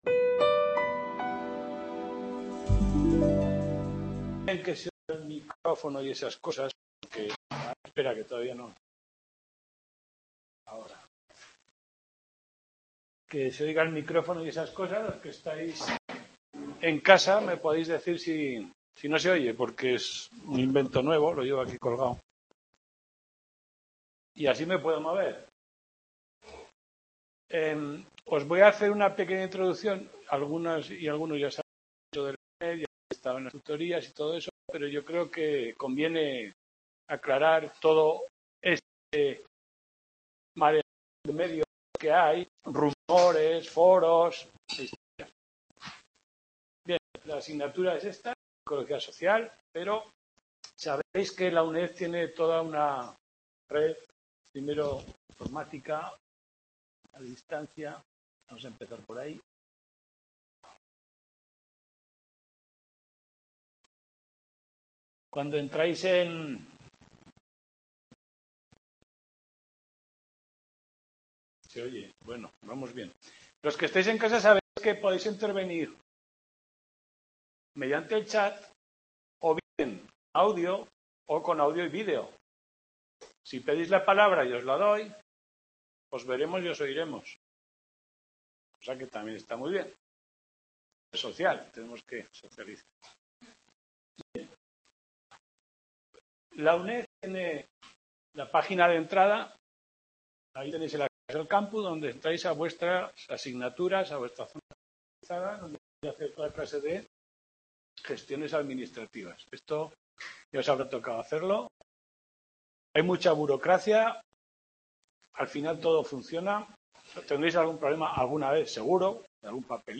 Tutoria Ps Social sesión 1